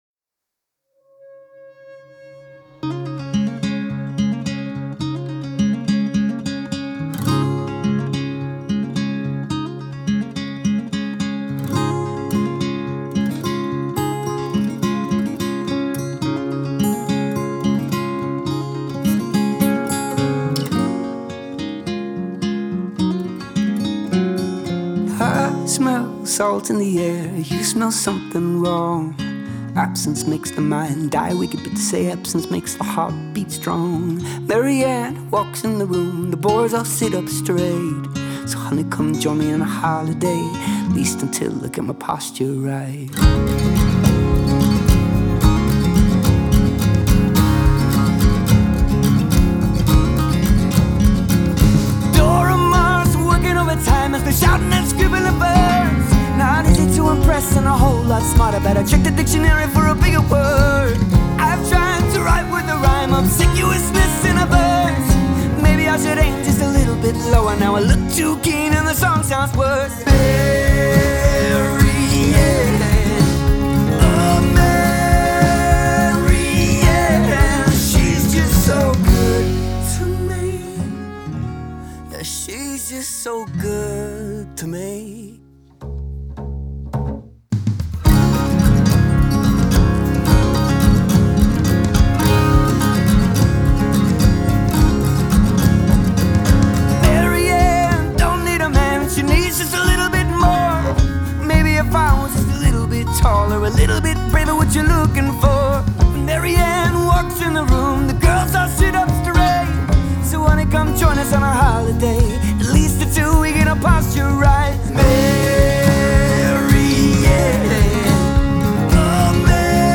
• Жанр: Folk